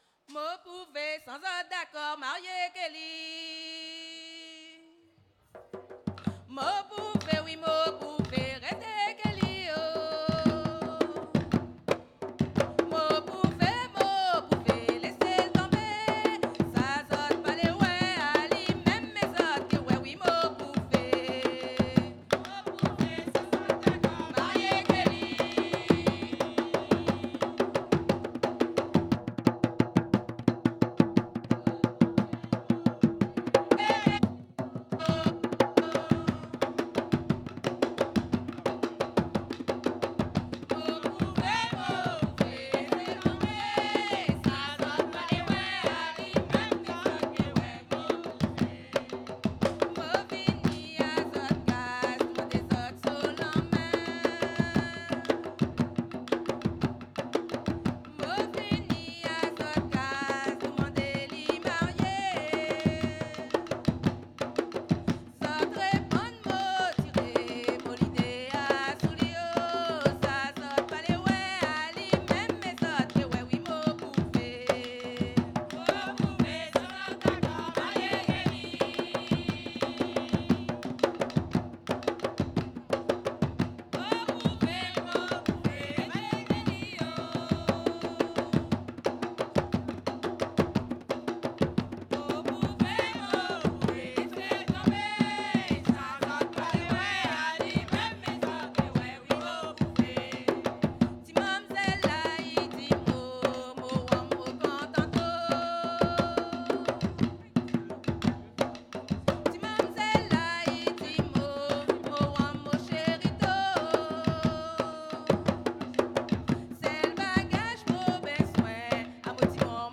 danse : grajévals (créole)
Pièce musicale inédite